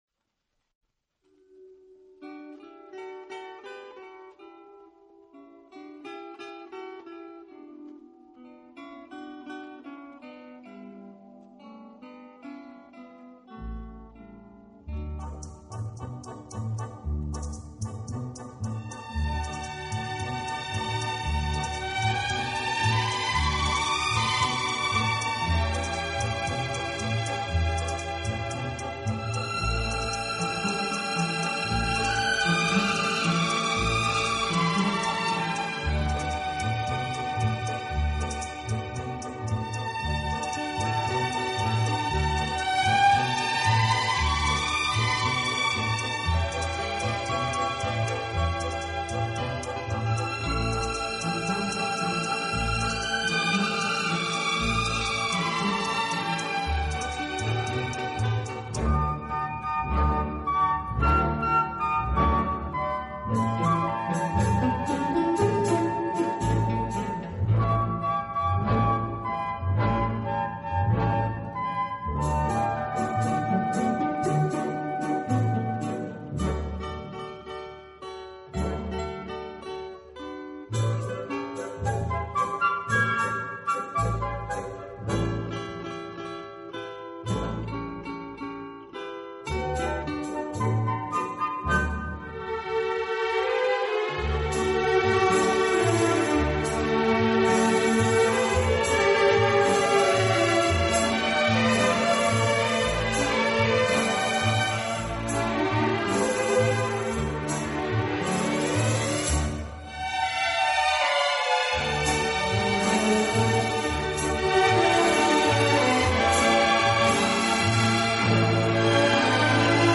Genre: Instrumental
舒展，旋律优美、动听，音响华丽丰满。